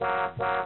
honkhonk-high.mp3